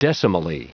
Prononciation du mot decimally en anglais (fichier audio)